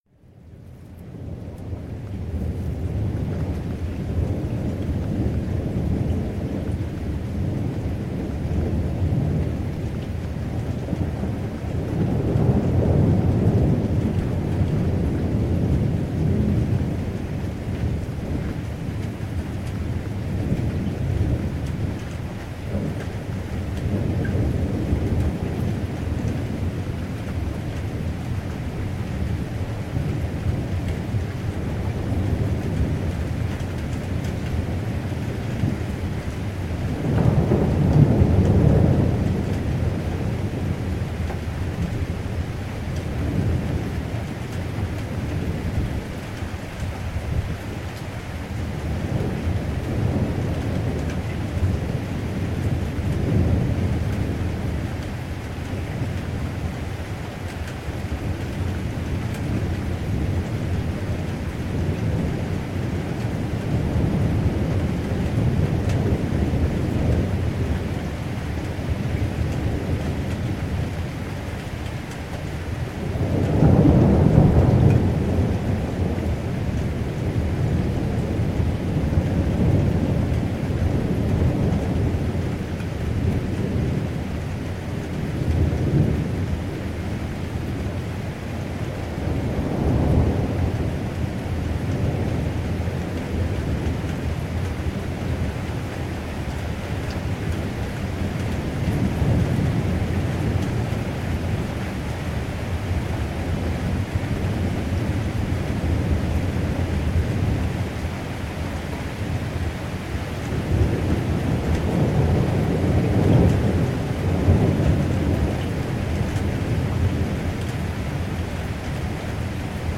Es ist windstill, dann fängt es nach und nach an zu donnern, kurze Zeit später fällt Flüssigkeit von oben herunter.
Das Grollen zieht sich über eine Viertelstunde ohne Pause hin, es ist ein Dauerdonner.
Hier folgt eine kleine Tonaufnahme, der Regen prasselt und etwas leiser im Hintergrund hört man ununterbrochen fünf Minuten lang den Donner.